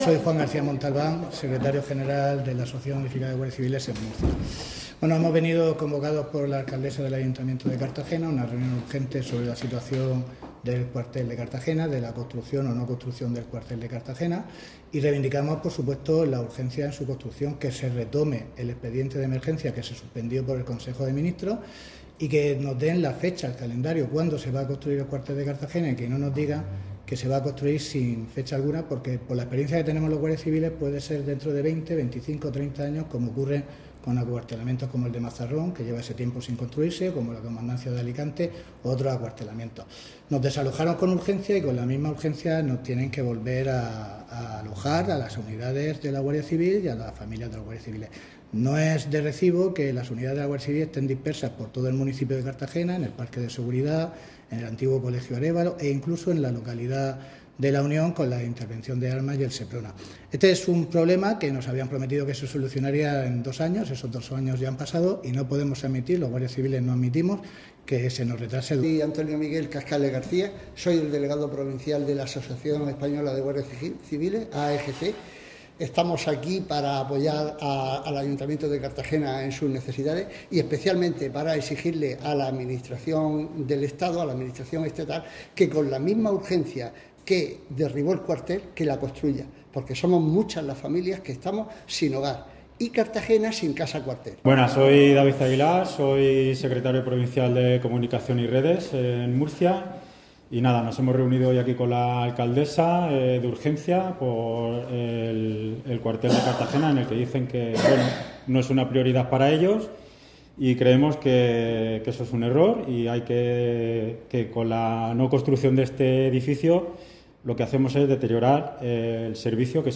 Audio: Declaraciones de Noelia Arroyo, PP (MP3 - 2,15 MB)